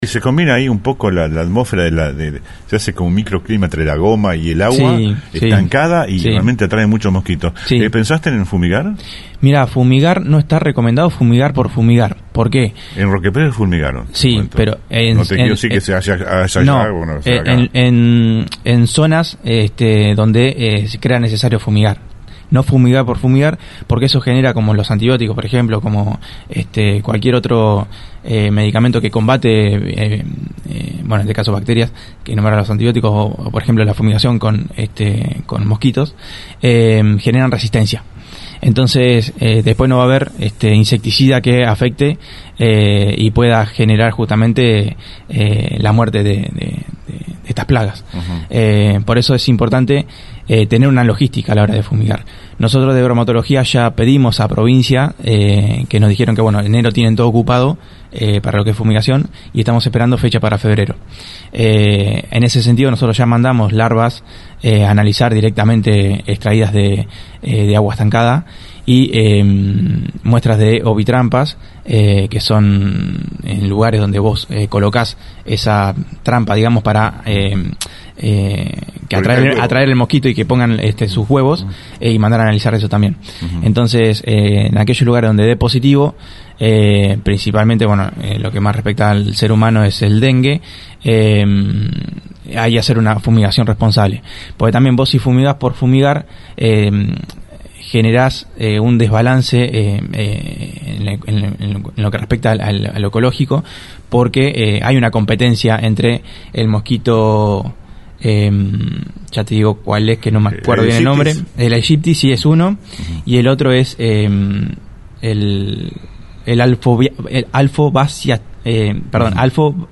Hace unos días, en diálogo con la 91.5 Eduardo Zapata, Secretario de Salud, afirmó que «se va a fumigar en zonas donde realmente sea necesario. No se trata de fumigar por fumigar porque luego algunas especies después pueden generar resistencia».